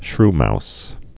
(shrmous)